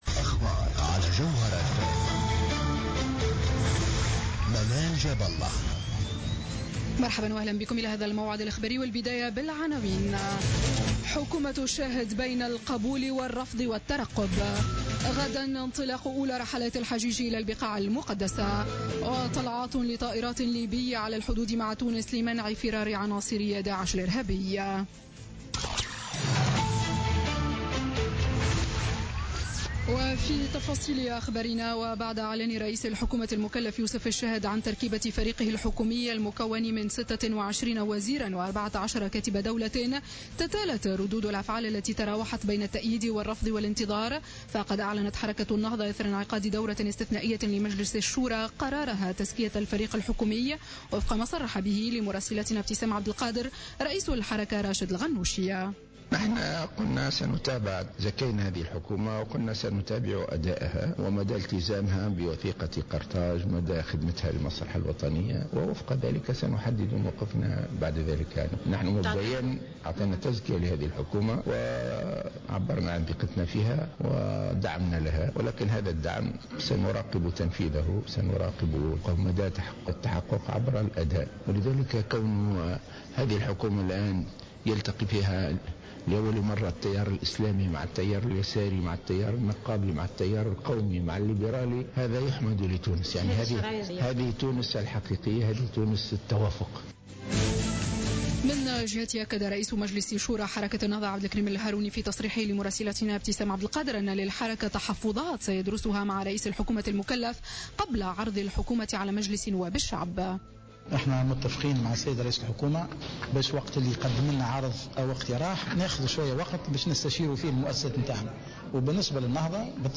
نشرة أخبار السابعة مساء ليوم الأحد 21 أوت 2016